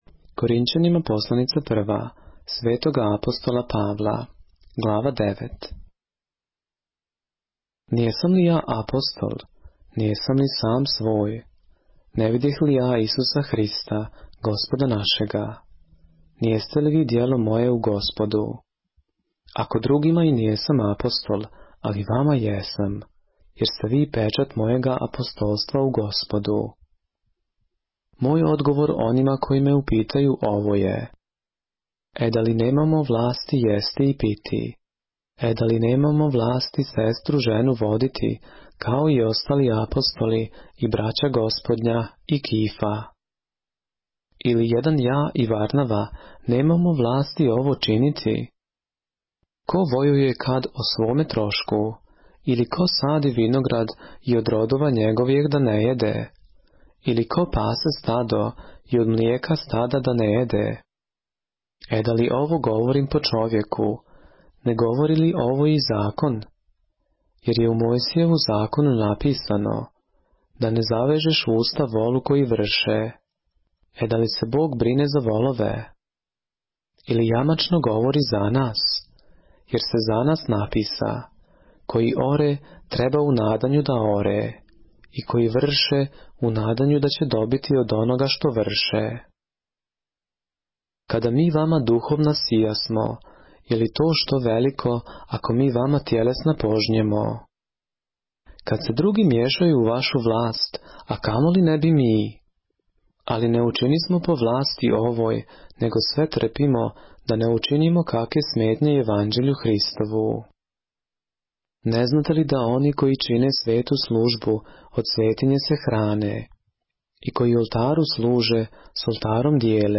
поглавље српске Библије - са аудио нарације - 1 Corinthians, chapter 9 of the Holy Bible in the Serbian language